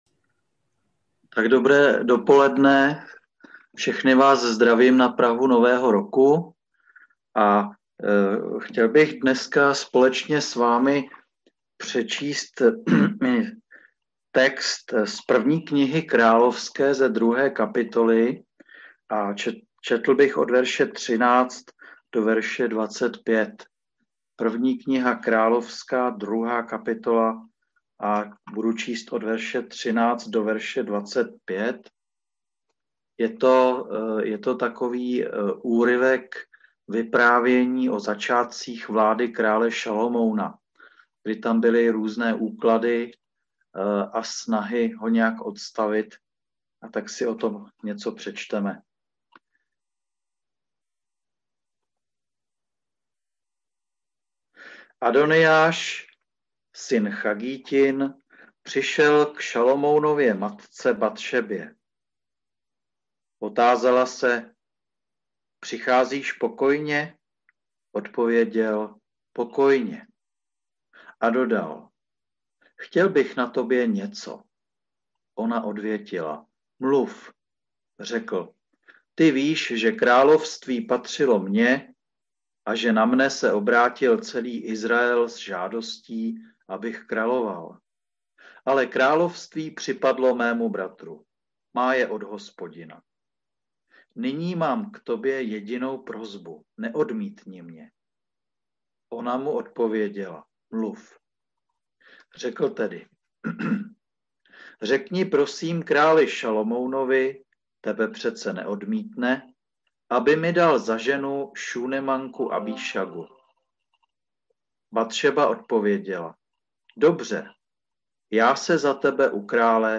Novoroční kázání 1.1.2021